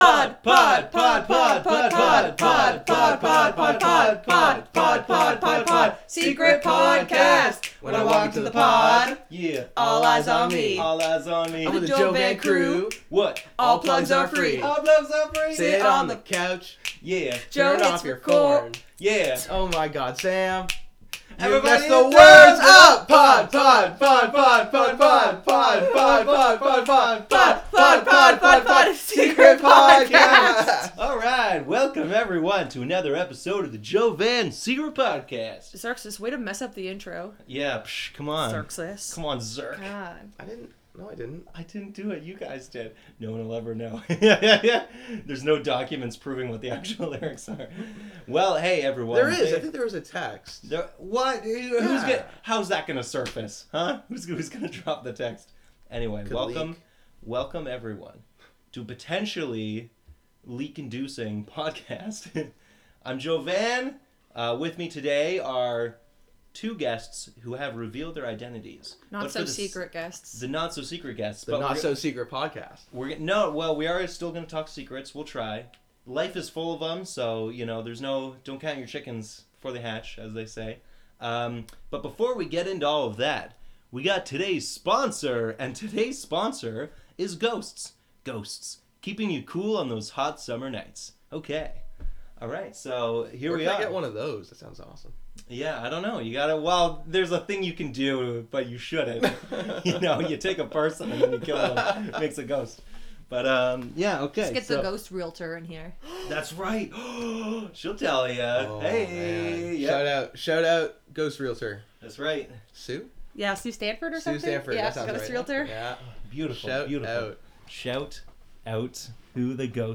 We sing only to jump head first into deep talks that have no end in relation to there being a possible conclusive answer.